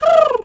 pgs/Assets/Audio/Animal_Impersonations/pigeon_2_call_08.wav at master
pigeon_2_call_08.wav